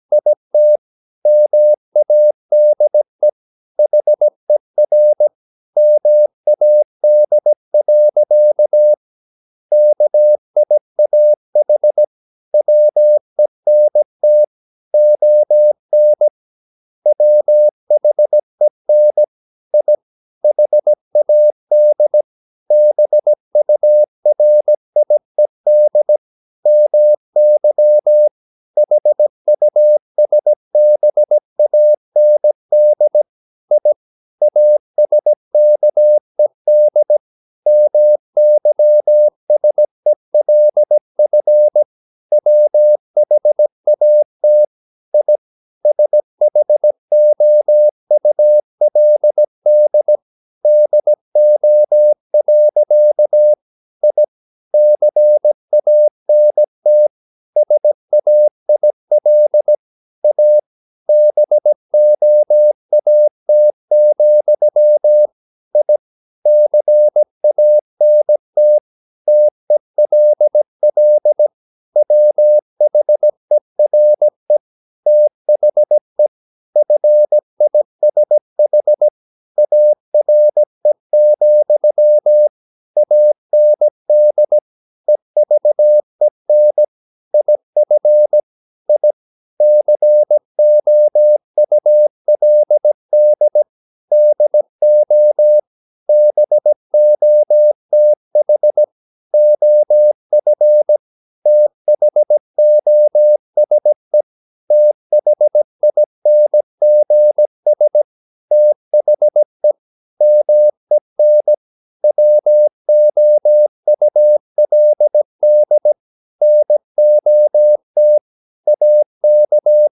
Never 17wpm | CW med Gnister